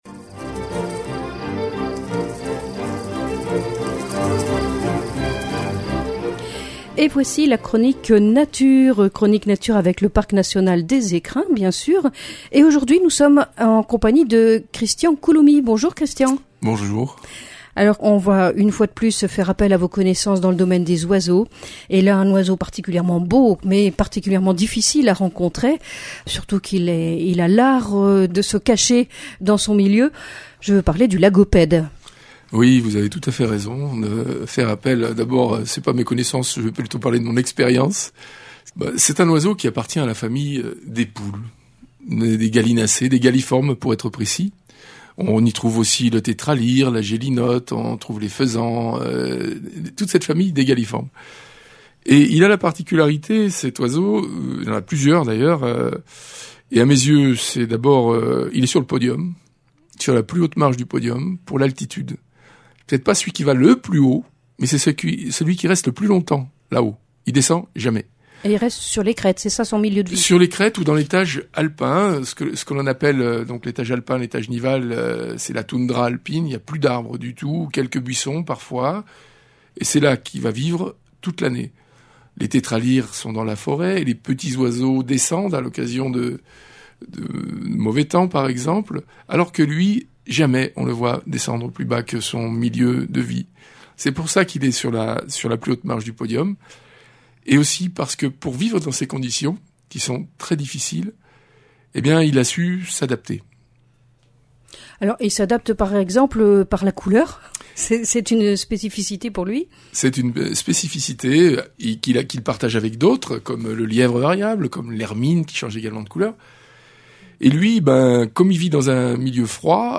Chronique nature Il fait partie de la famille des gallinacés et ne vit qu'en haute montagne.